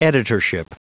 Prononciation du mot : editorship